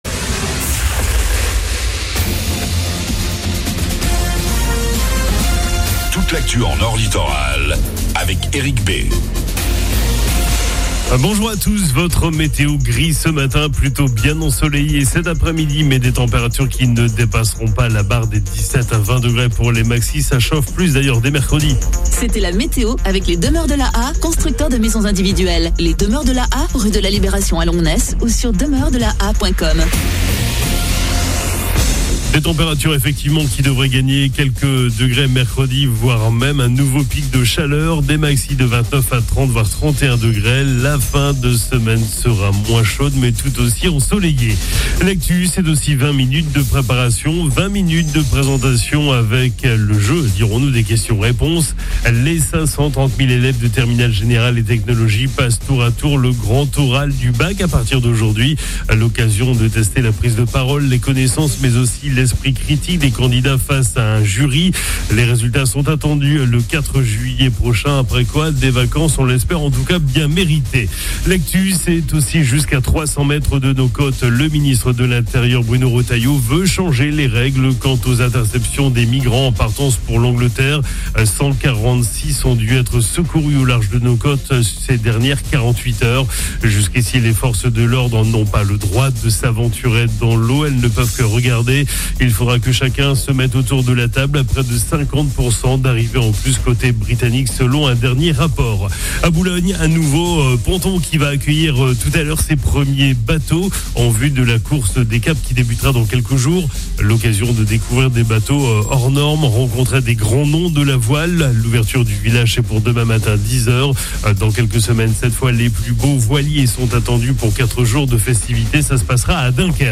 FLASH 23 JUIN 25